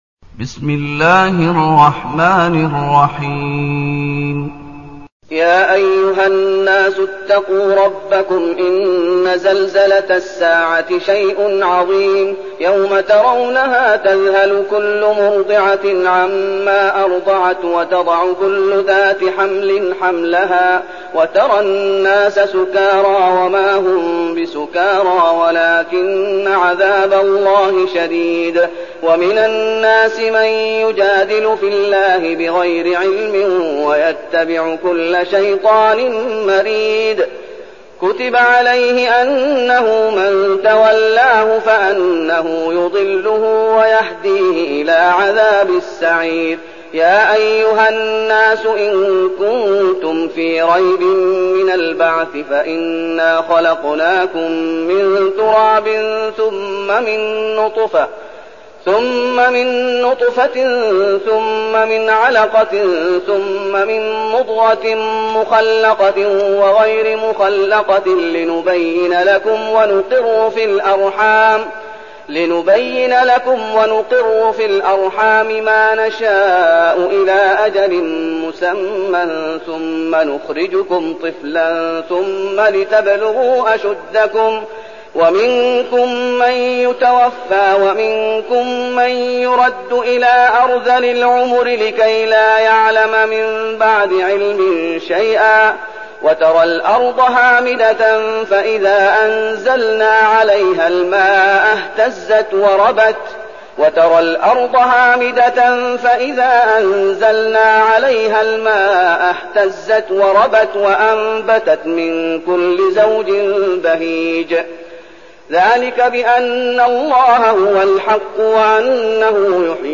تاريخ النشر ١ محرم ١٤١٠ المكان: المسجد النبوي الشيخ: فضيلة الشيخ محمد أيوب فضيلة الشيخ محمد أيوب سورة الحج